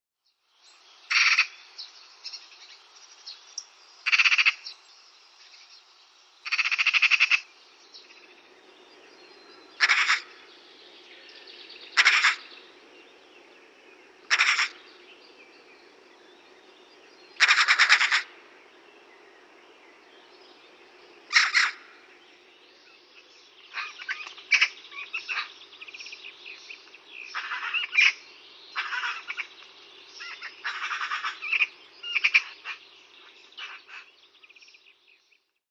Harakka
Kuuntele harakan ääntä.